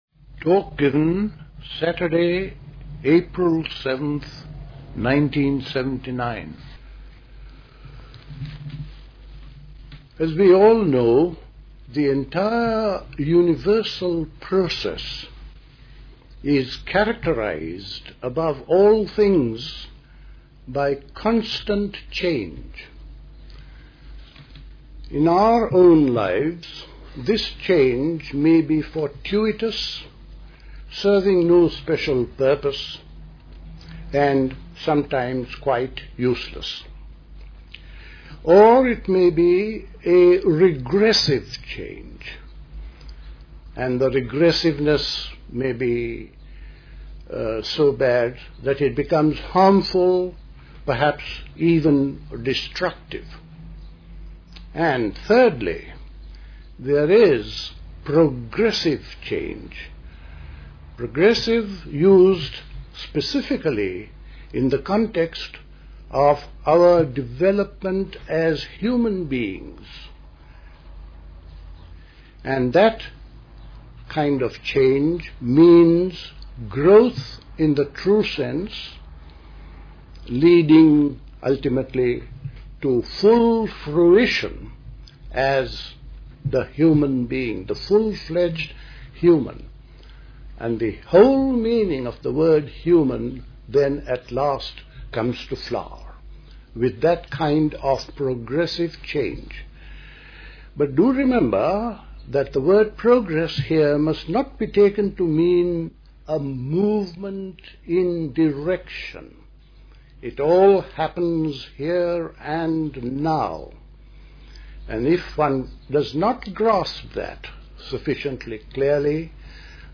A talk
at Dilkusha, Forest Hill, London on 7th April 1979